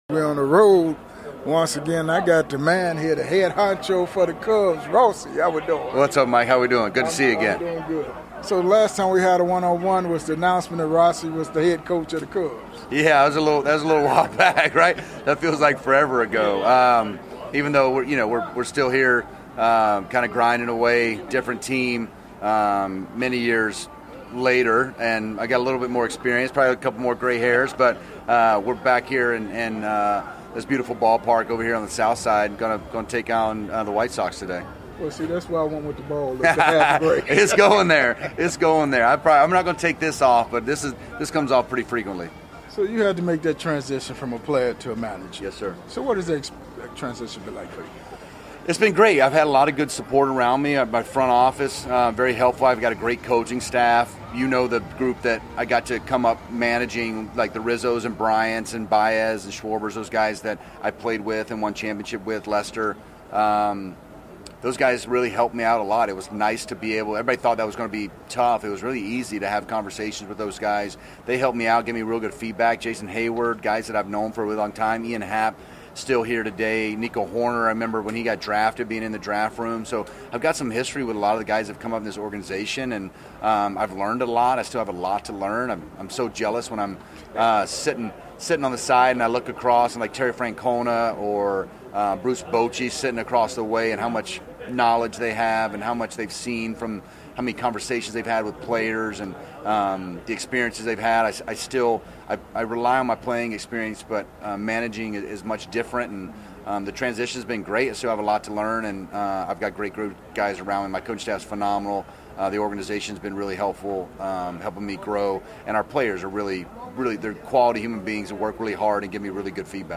Classic MLB Interviews with the stars of the games